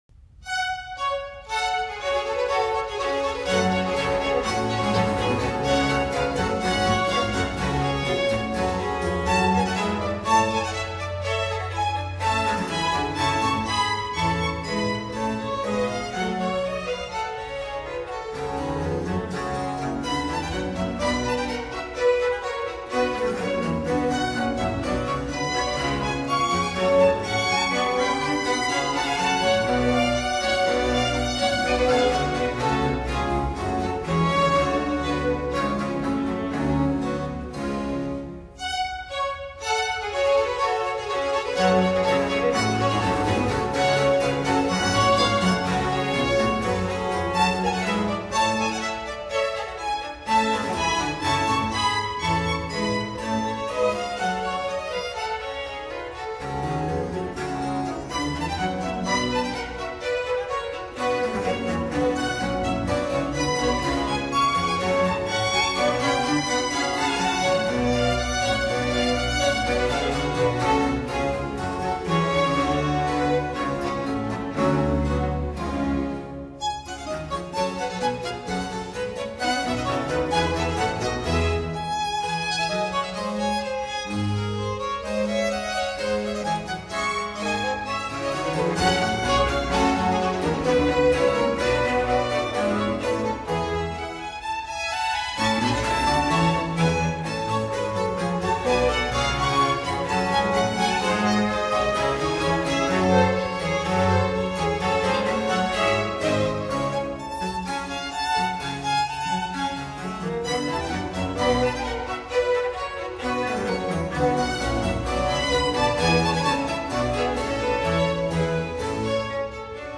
巴洛克小提琴協奏曲輯